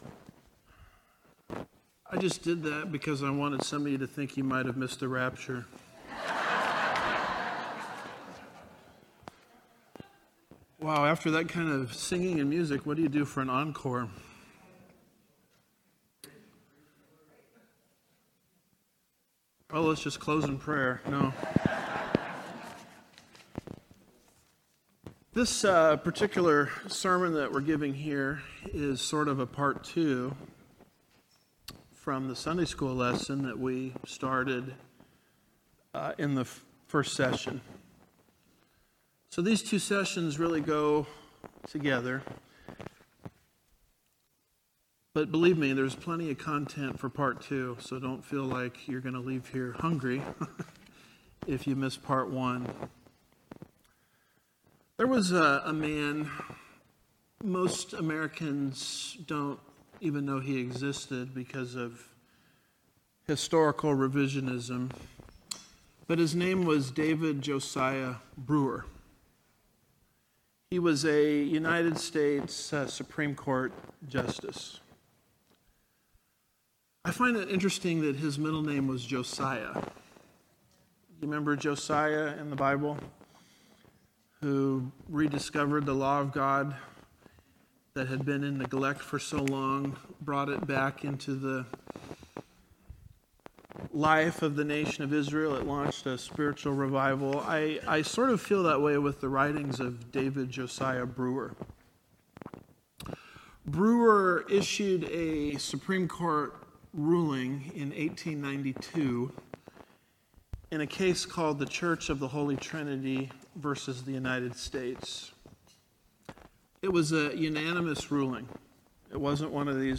– Part 2 Home / Sermons / Is America a Christian Nation?